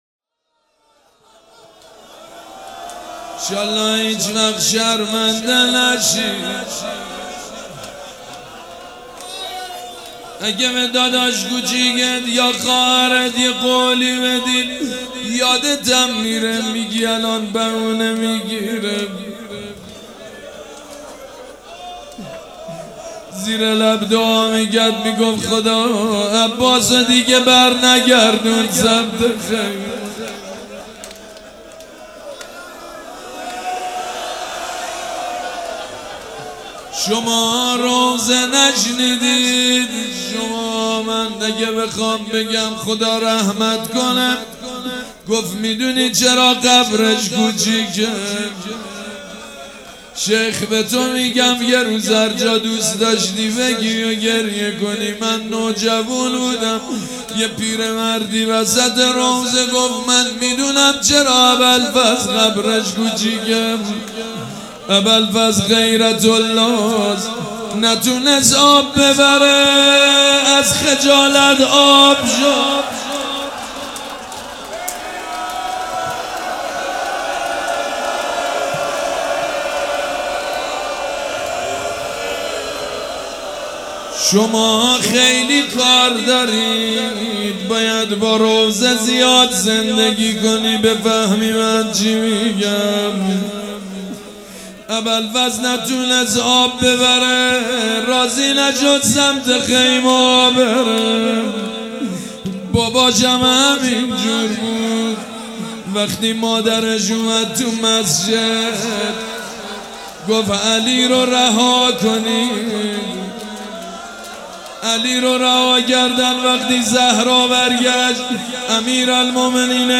روضه
مراسم عزاداری شب چهارم